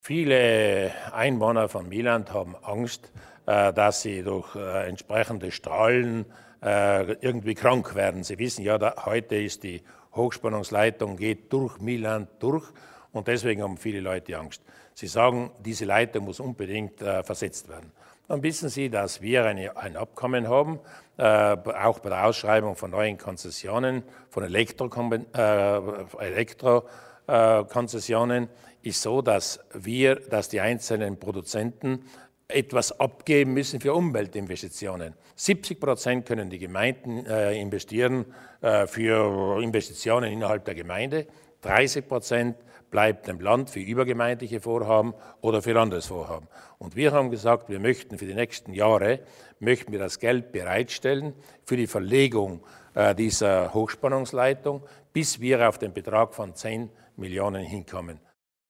Landeshauptmann Durnwalder über die Hilfen für die Bevölkerung von Milland